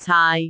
speech
syllable
pronunciation
caai6.wav